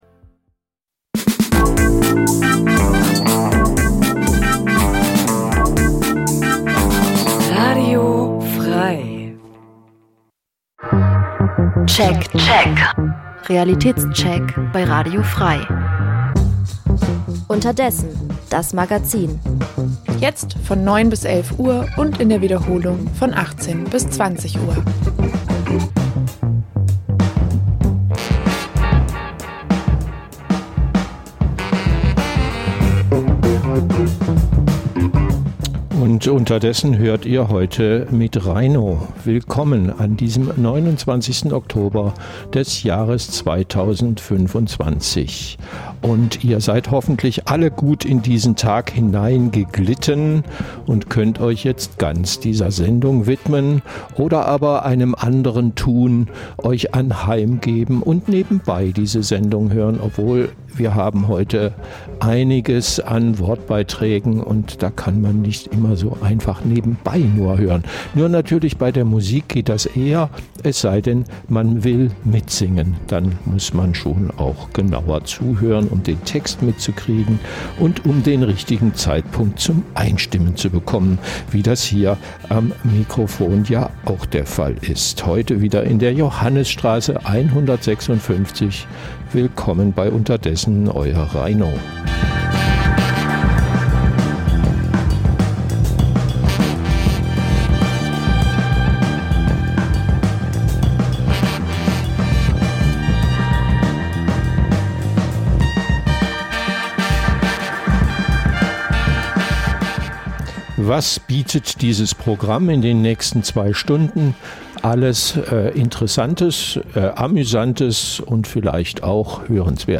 Das tagesaktuelle Livemagazin sendet ab 2024 montags bis freitags 9-11 Uhr. Jeden Tag von anderen Moderator:innen und thematisch abwechslungsreich best�ckt.